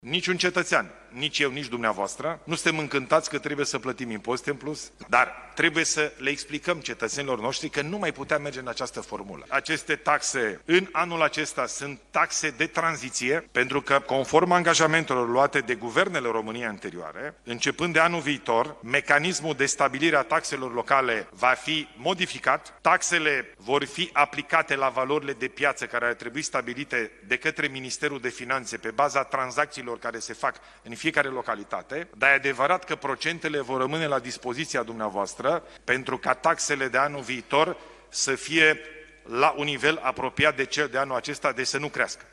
Taxele locale vor fi modificate de anul viitor, anunță premierul, la dezbaterea cu primarii locali din țară care are loc la Palatul Parlamentului.